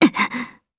Valance-Vox_Damage_jp_05.wav